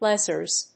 発音記号・読み方
/ˈlɛsɝz(米国英語), ˈlesɜ:z(英国英語)/